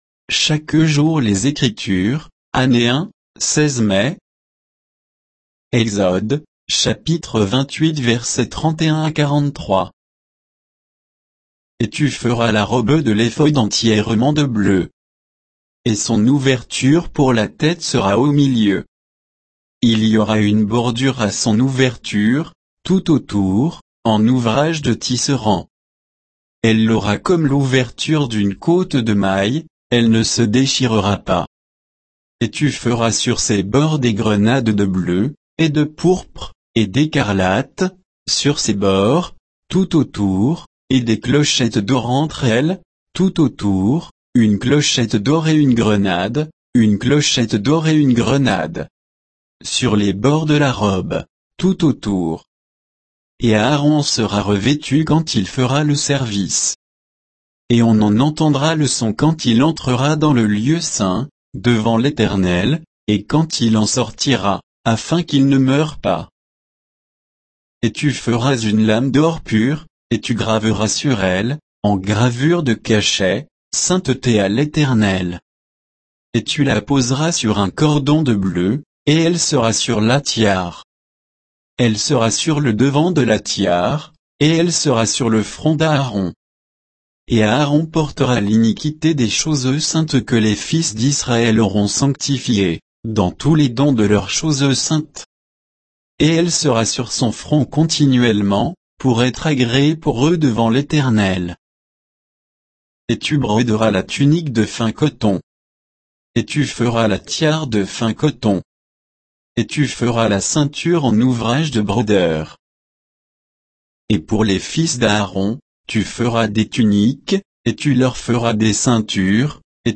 Méditation quoditienne de Chaque jour les Écritures sur Exode 28